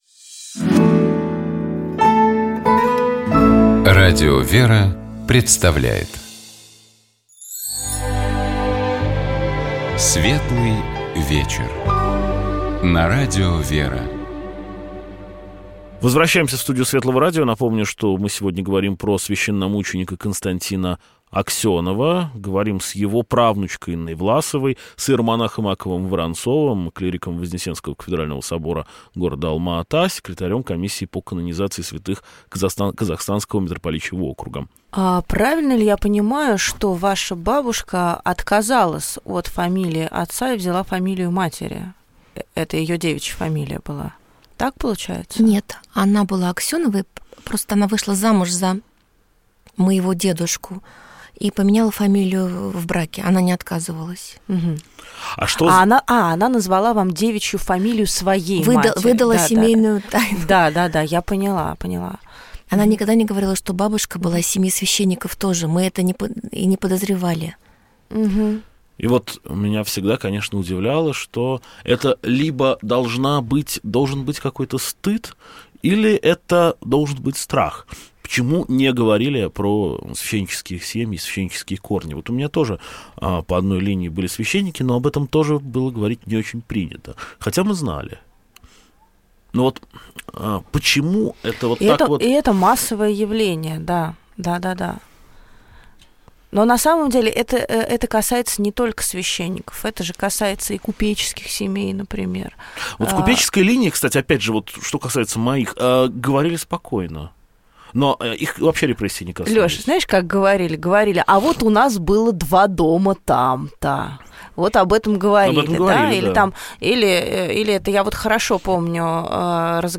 Разговор шел о непростом жизненном пути и служении священномученика Константина Аксенова, который был расстрелян в 1937 году за исповедование своей веры.